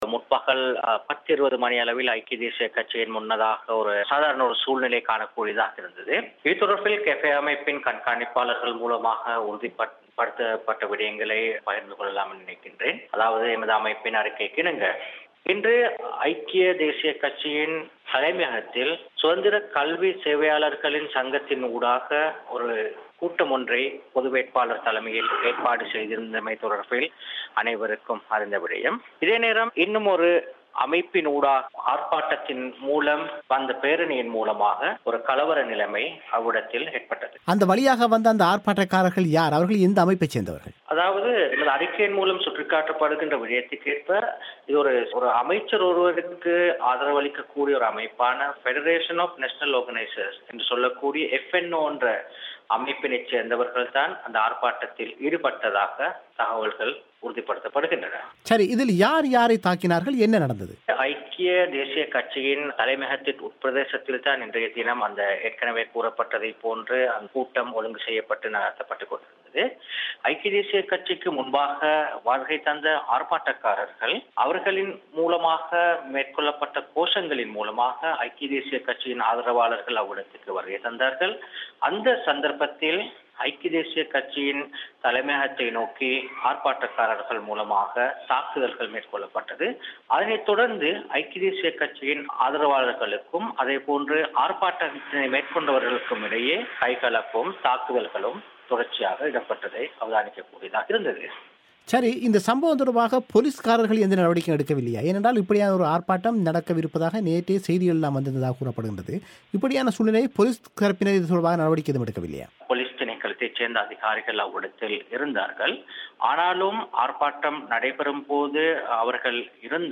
செவ்வி.